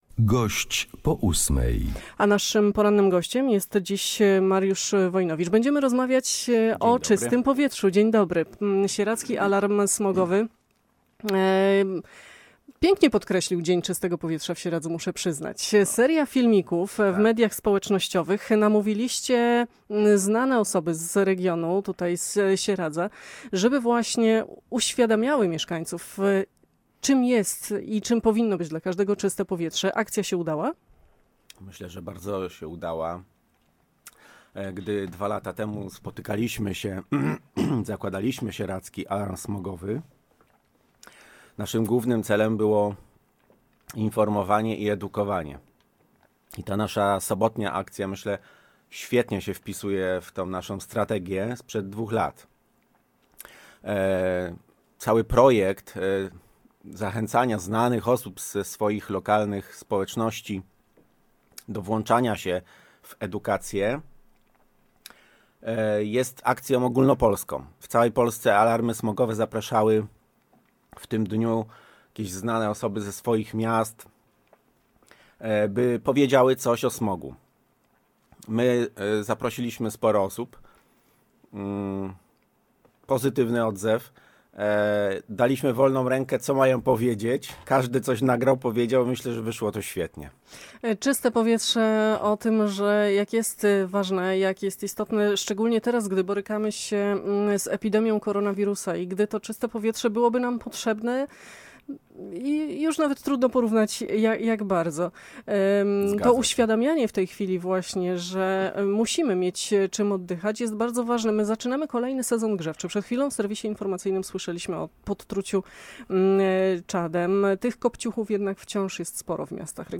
przekonywał w studiu Radia Łódź Nad Wartą